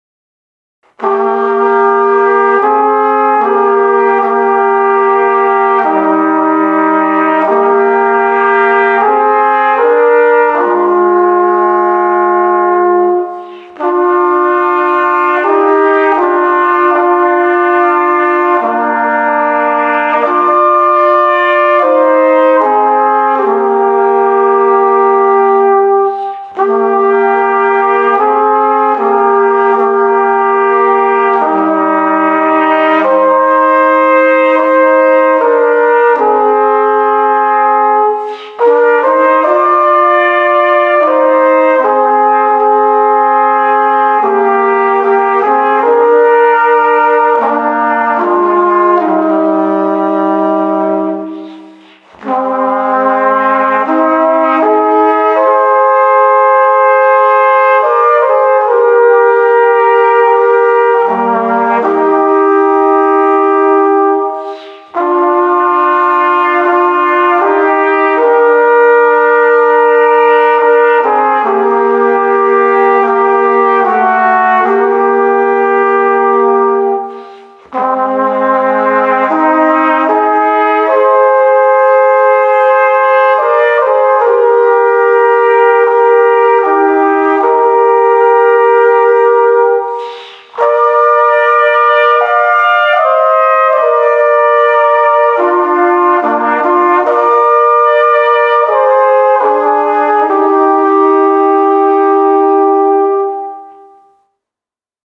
Gattung: für zwei Trompeten oder Flügelhörner